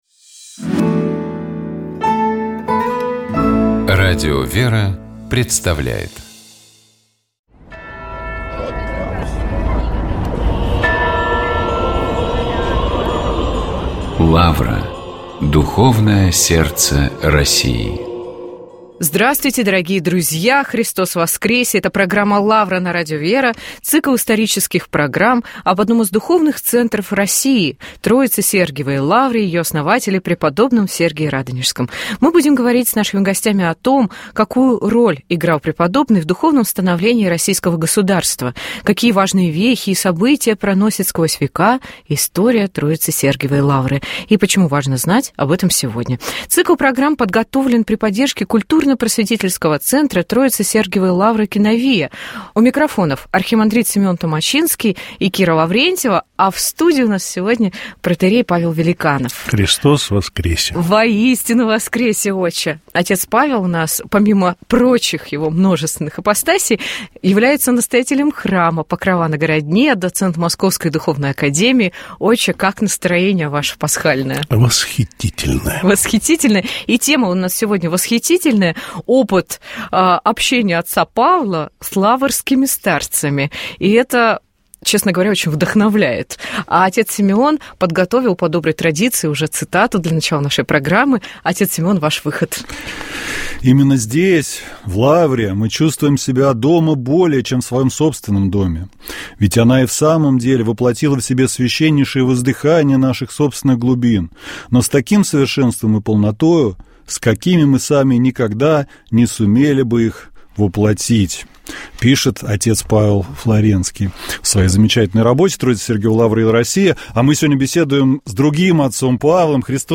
Тексты богослужений праздничных и воскресных дней. Всенощное Бдение. 22 февраля (вечер 21 февраля) 2026г.